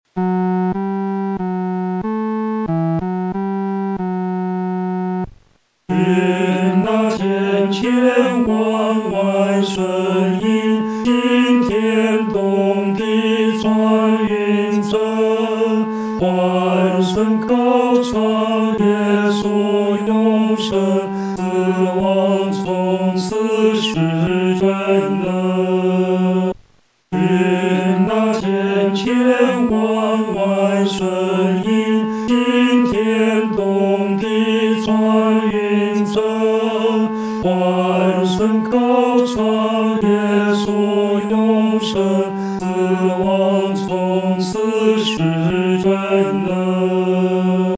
男高合唱